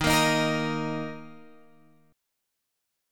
D# 5th
D#5 chord {11 13 13 x 11 11} chord